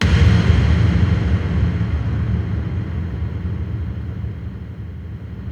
Index of /90_sSampleCDs/Roland - Rhythm Section/TOM_Rolls & FX/TOM_Tomish FX
TOM BIG G06R.wav